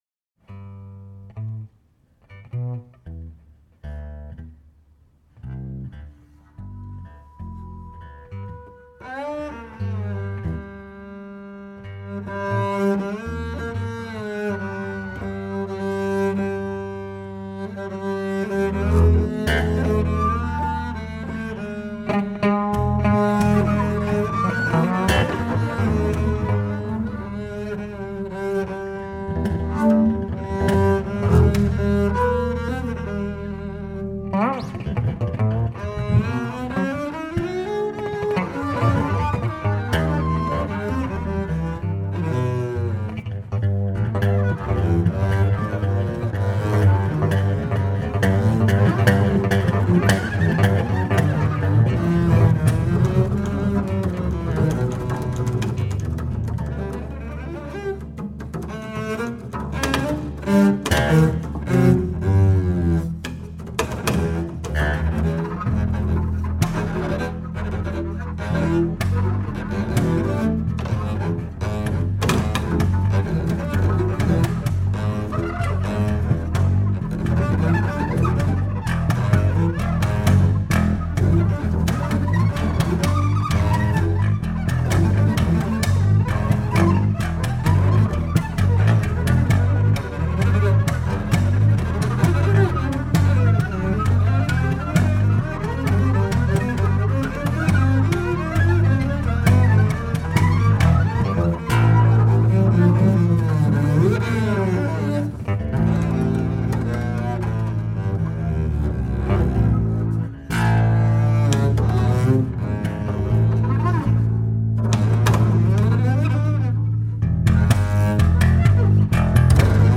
ベーシスト3人によるトリオ！